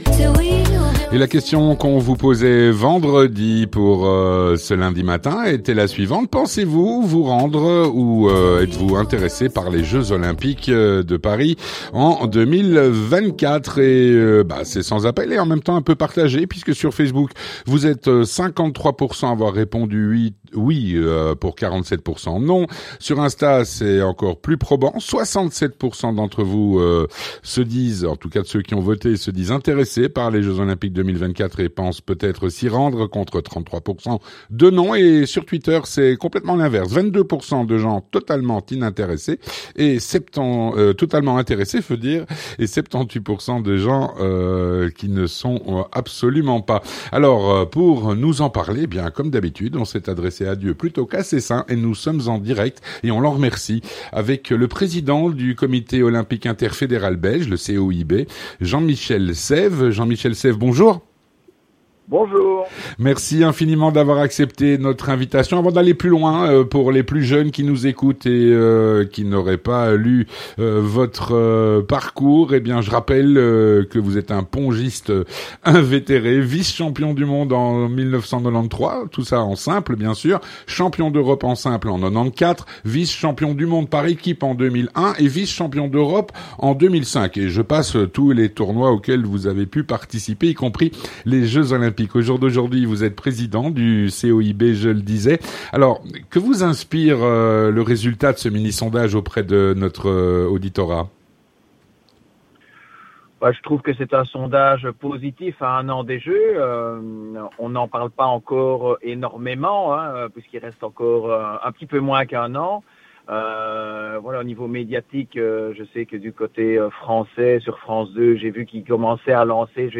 Jean-Michel Saive, Président du Comité Olympique Interfédéral de Belgique (COIB), répond à "La Question Du Jour"